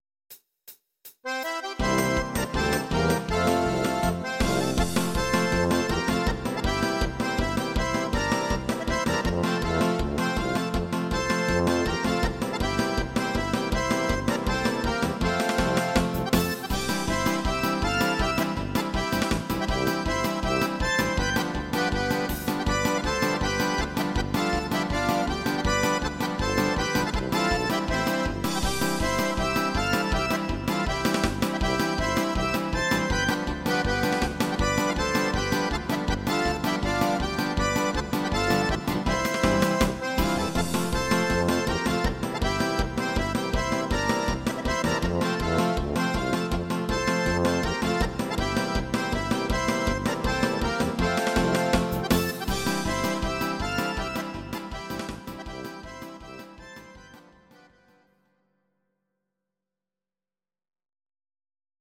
instr. steirische Harmonika